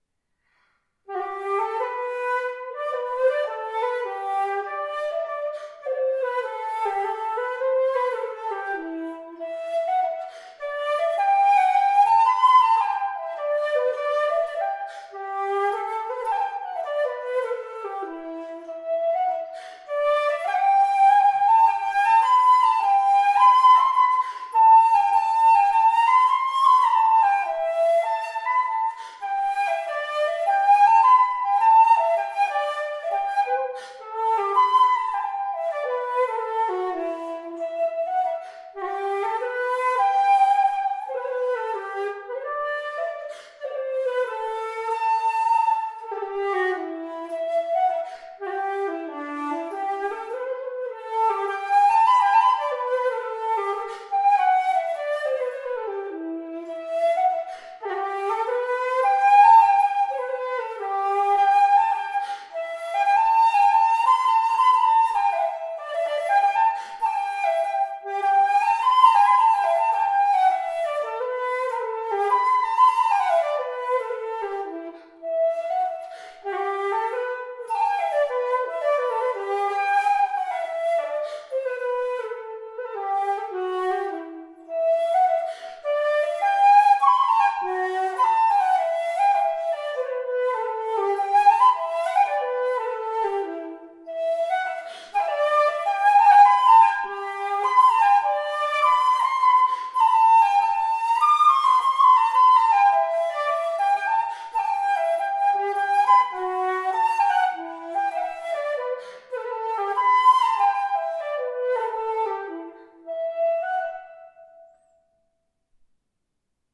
Ivory flute – CAHUSAC Junior
Description Four-part ivory flute by Cahusac Junior with one silver key
Playing this flute is a delightful experience, producing a wonderfully sweet sound. It has a pitch of a=440 Hz and offers excellent intonation.